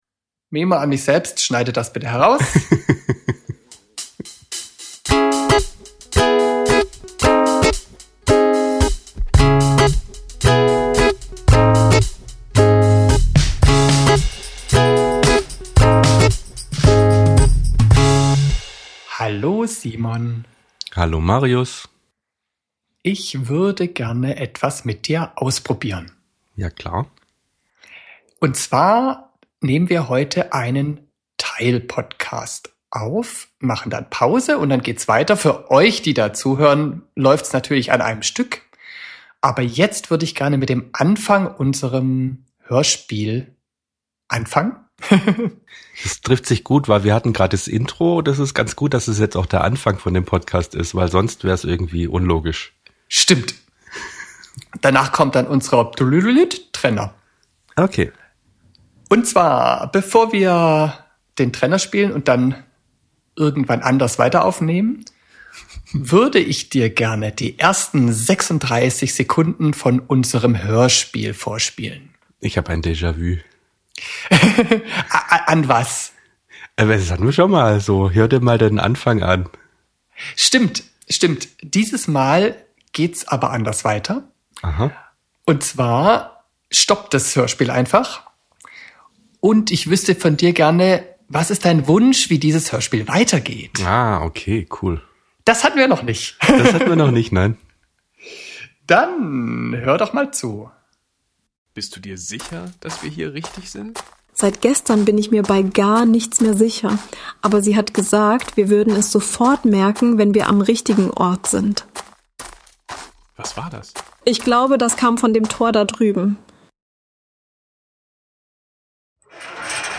Unvollendetes Hörspiel ~ Audiodidakten Podcast